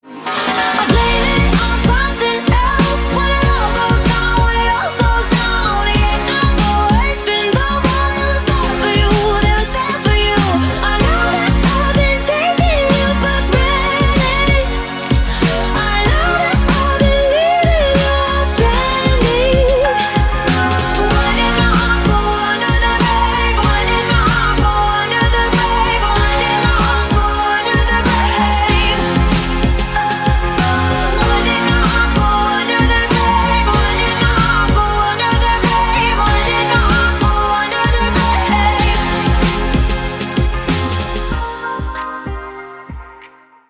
dance
vocal